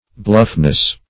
Search Result for " bluffness" : Wordnet 3.0 NOUN (1) 1. good-natured frankness ; The Collaborative International Dictionary of English v.0.48: Bluffness \Bluff"ness\, n. The quality or state of being bluff.